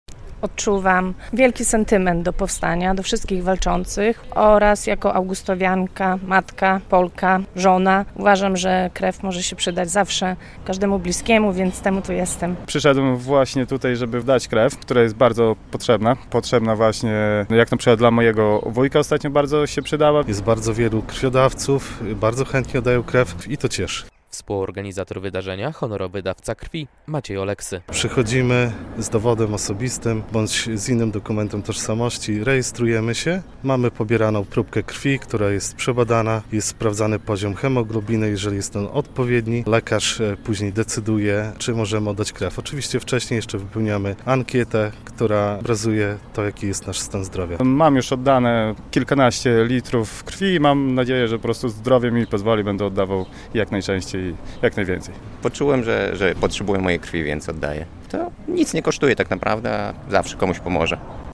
Akcja "Augustów oddaje krew za Warszawę" - relacja